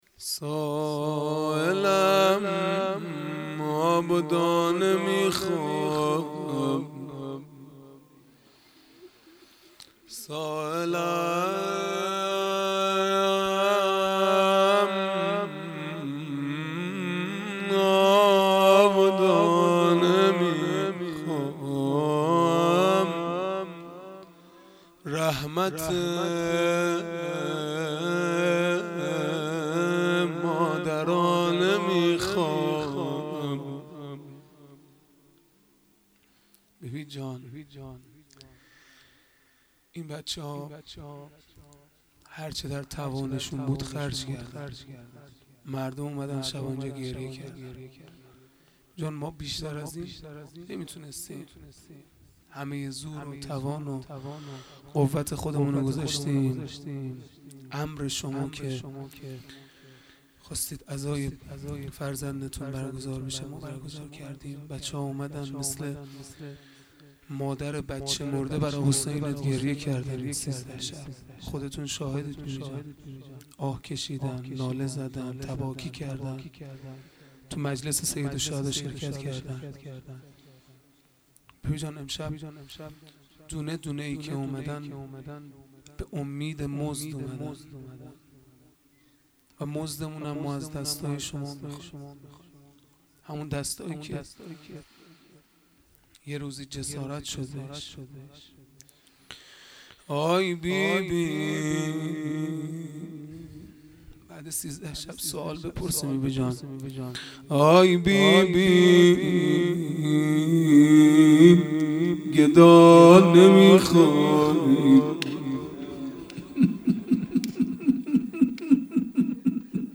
مناجات پایانی | سائلم آب و دانه میخواهم | شنبه ۳۰ مرداد ۱۴۰۰
دهه اول محرم الحرام ۱۴۴۳ | شب سیزدهم | شنبه ۳۰ مرداد ۱۴۰۰